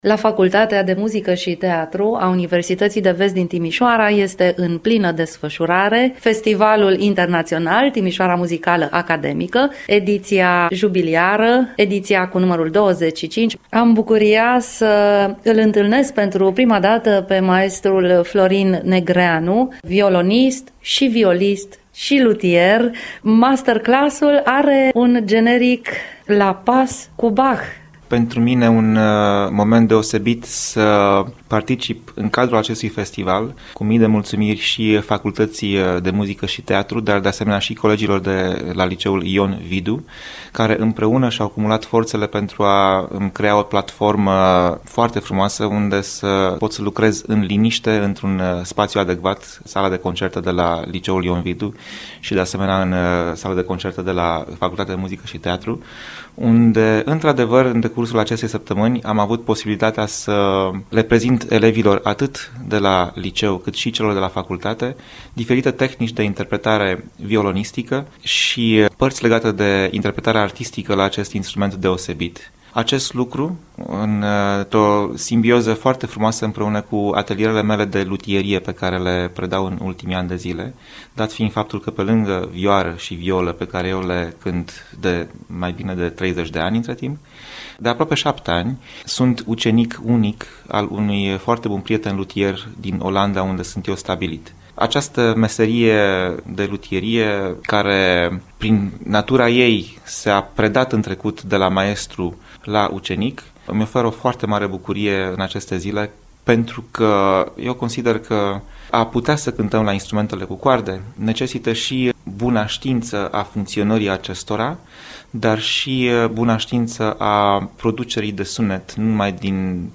INTERVIU/ La pas cu Bach, atelier de măiestrie în cadrul Festivalului Internațional Timişoara Muzicală Academică - Radio România Timișoara